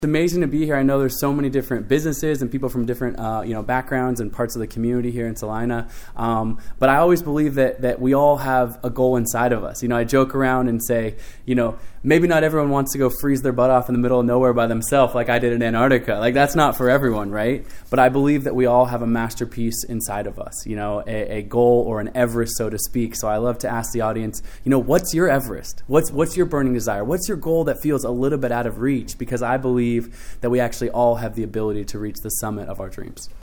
O’Brady, the athlete, adventurer, and author, was the featured speaker at the Salina Area Chamber of Commerce Annual Banquet.